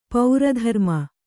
♪ paura dharma